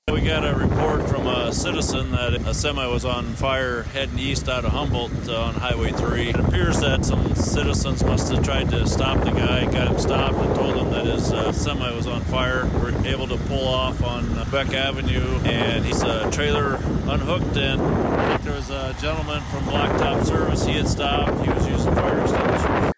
Humboldt County Sheriff Dean Kruger provided a statement on scene at the intersection of IA-3 and Quebec Ave.